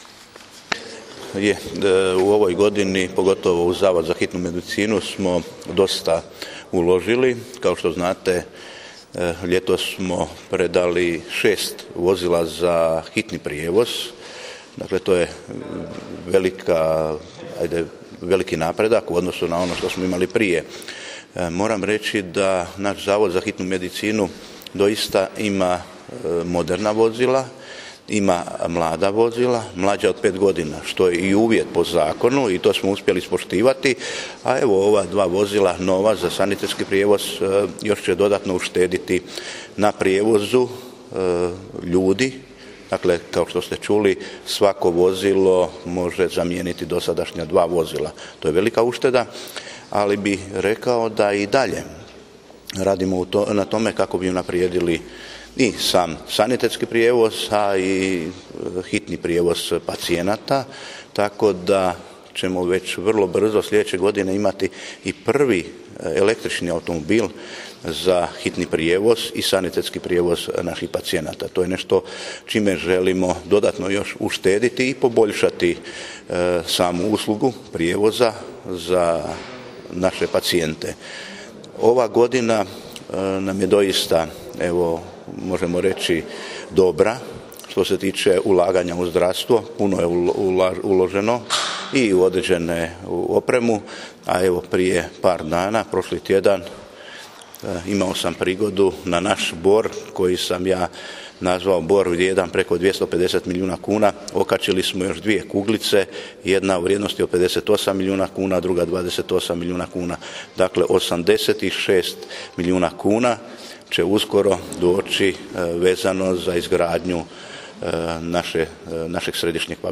Izjavu župana u ovoj prigodi poslušajte ovdje: